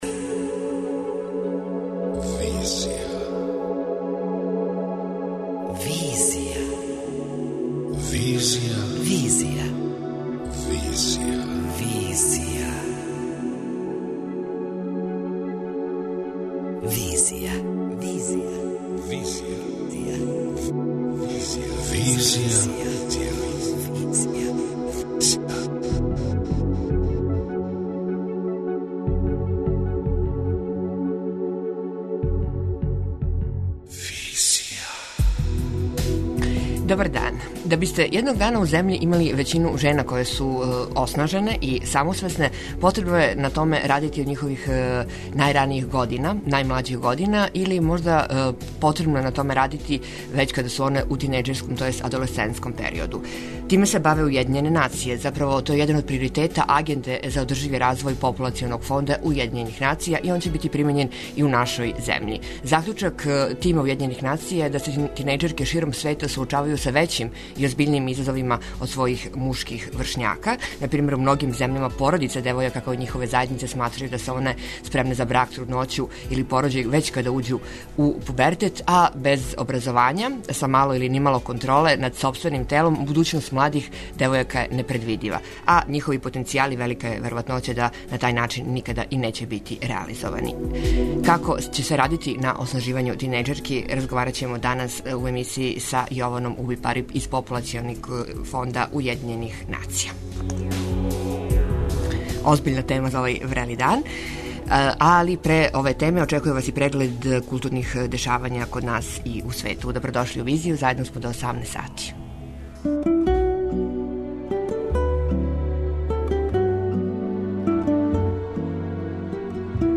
преузми : 27.37 MB Визија Autor: Београд 202 Социо-културолошки магазин, који прати савремене друштвене феномене.